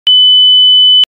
Sound Pressure Output (dB @ 10cm)70
Frequency (Hz)3000
Sine-3000Hz-Audio-Only.mp3